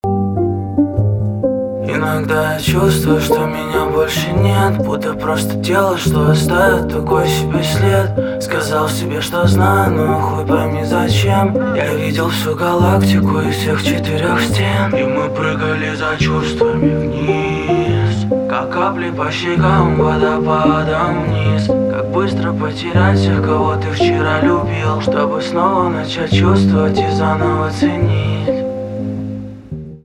русский рэп
пианино
грустные , печальные